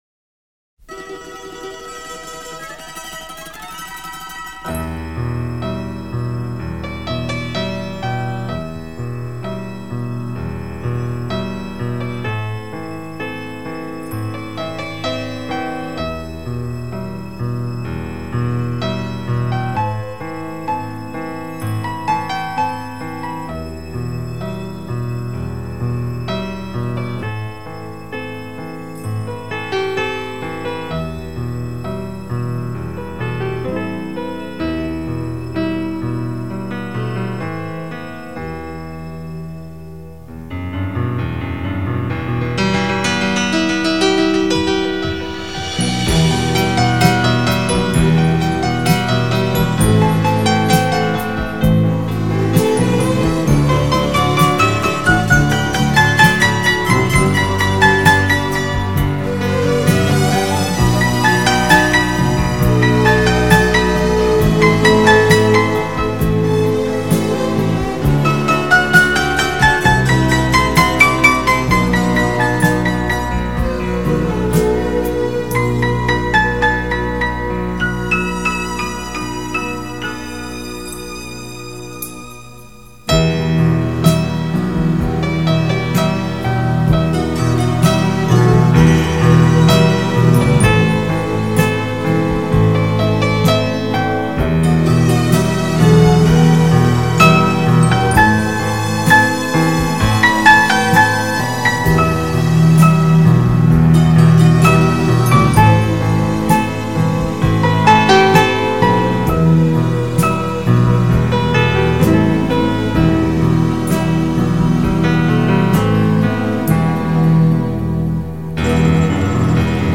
경음악
장르: Pop